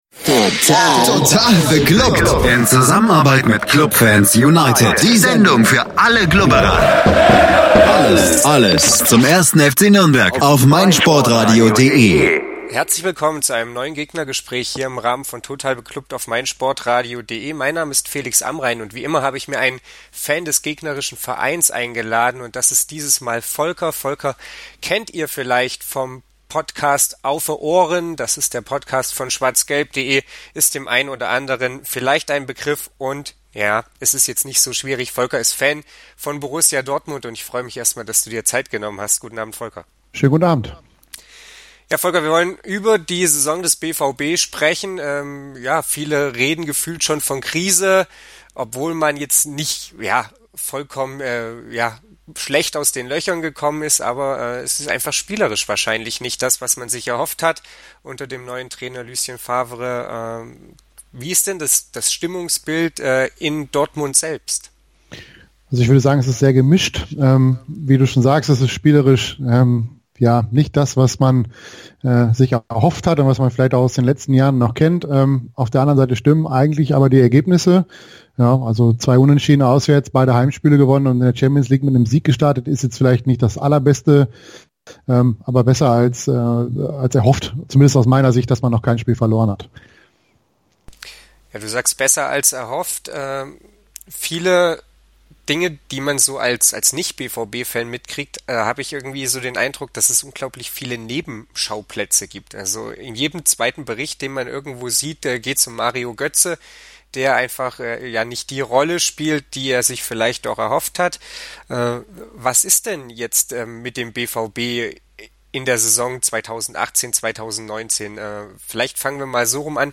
gegnergespraech-dortmund.mp3